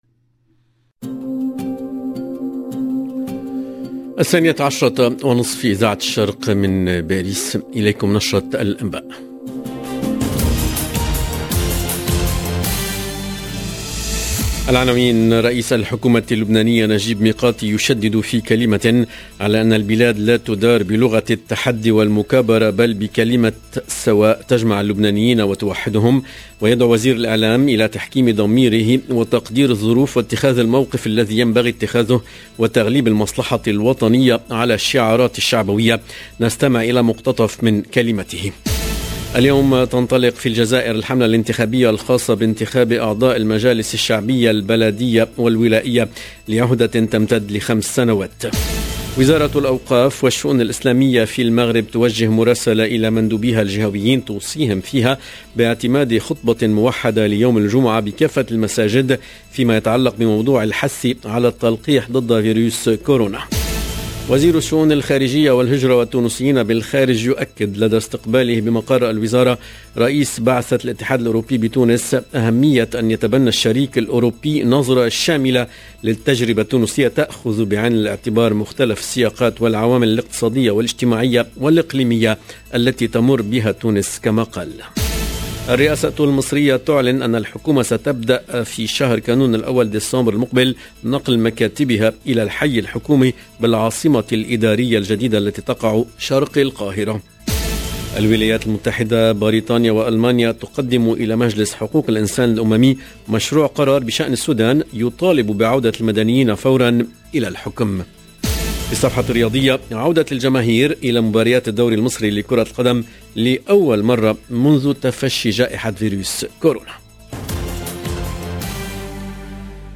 EDITION DU JOURNAL DE 12H30 EN LANGUE ARABE DU 4/11/2021